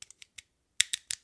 工具 剃须刀片 Out05
描述：正在处理盒式切割机的声音。 此文件已标准化，大部分背景噪音已删除。没有进行任何其他处理。
Tag: 切割机 缩回 刀片 工具 延伸 剃须刀